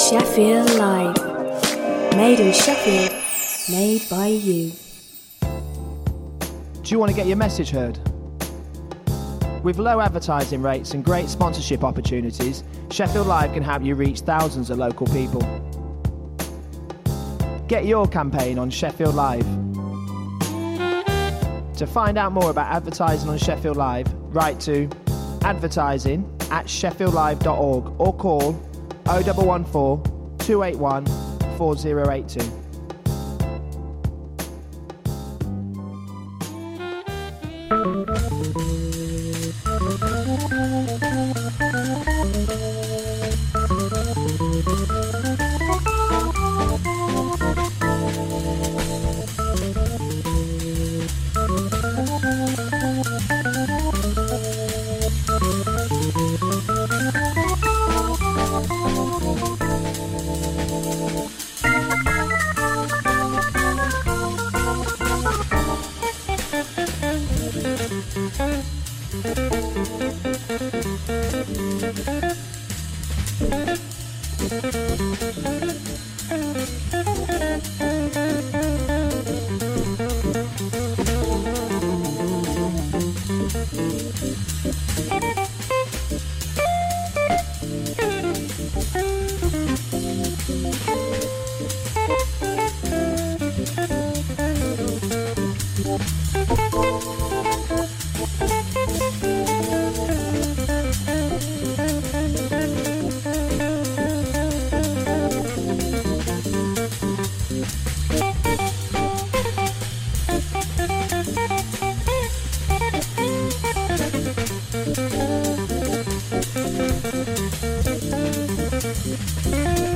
An extensive weekly exploration of modern jazz; be-bop, hard bop, West Coast, Latin, bossa nova, vocals, fusion and soul.